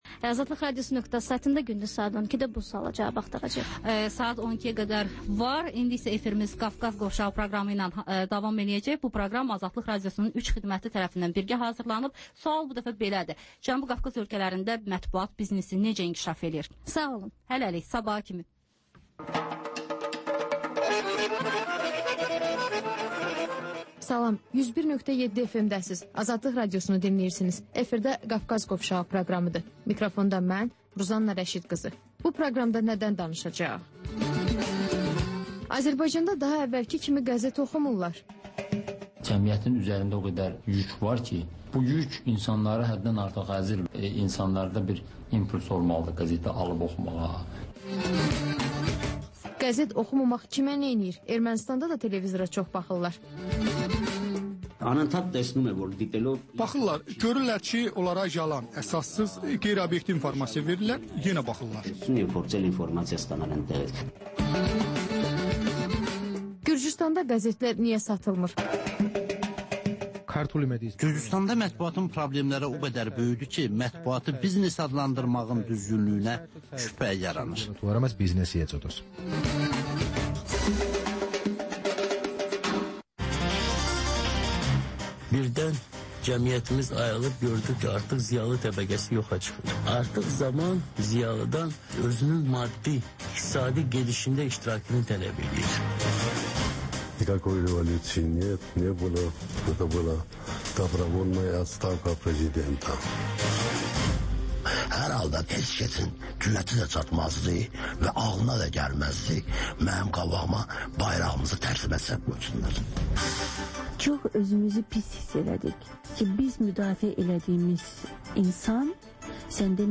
Ölkənin tanınmış simalarıyla söhbət